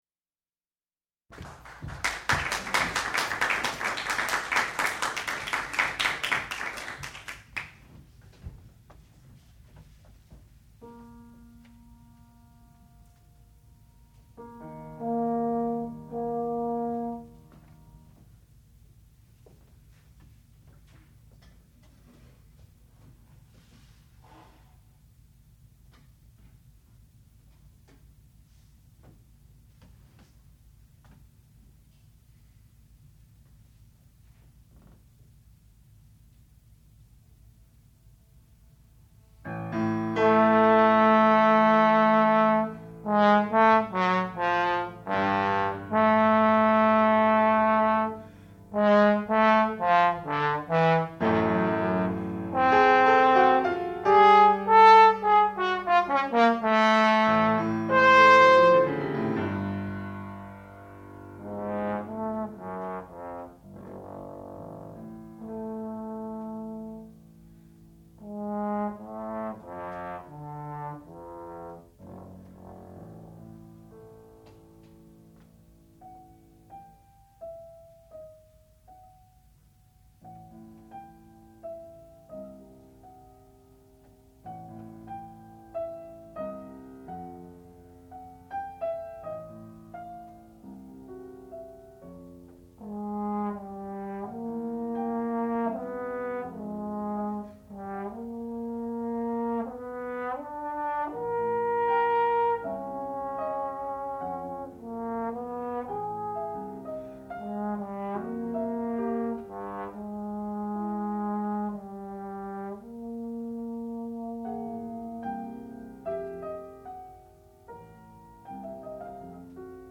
sound recording-musical
classical music
Master's Recital
trombone